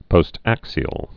(pōst-ăksē-əl)